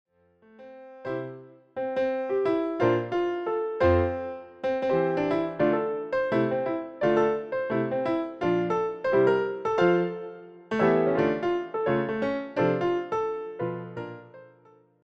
solo piano takes on Broadway material